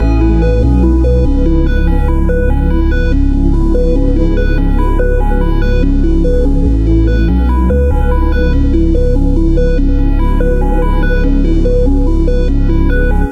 (2) NAV PROBLEMS BPM 144.wav